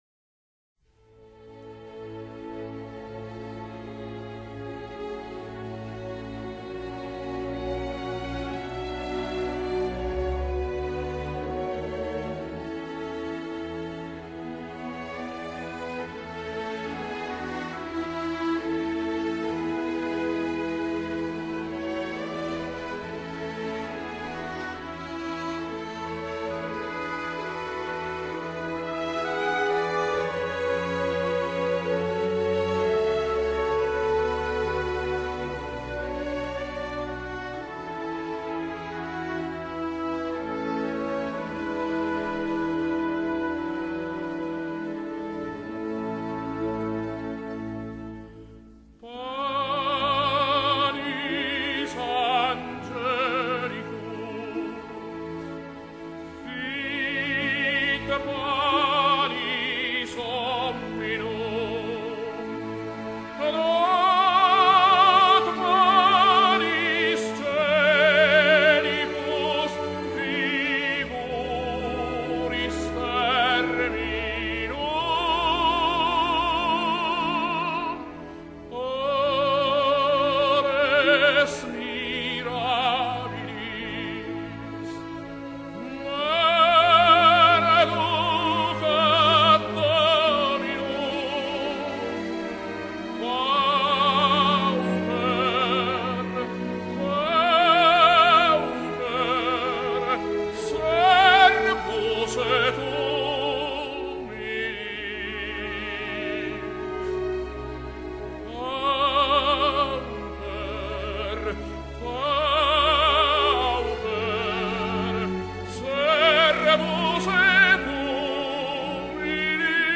Répétition SATB par voix
Solo Tenor